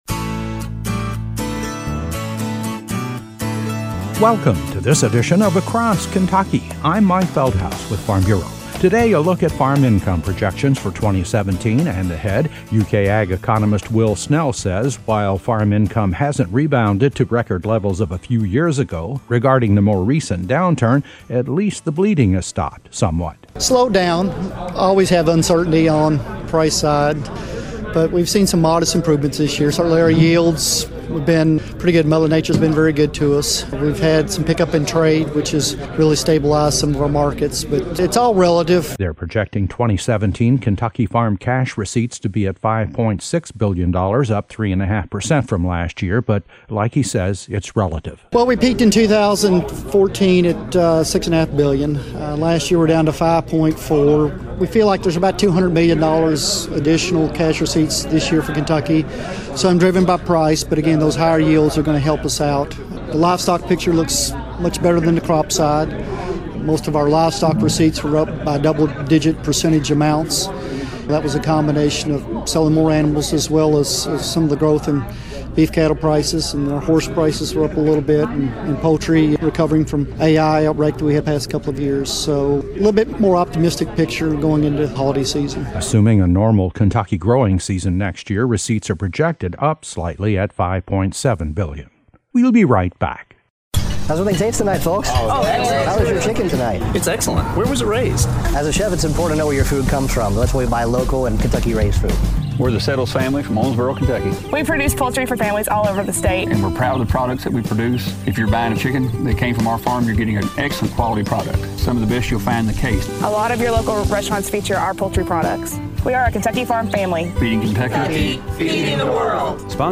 A report on UK’s projections that 2017 Ky farm cash receipts will total $5.6 billion, which is a 3.5 percent increase from 2016.  Preliminary indications also point to a modest increase in net farm income levels.